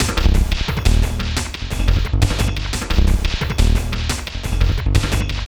B + D LOOP 2 1.wav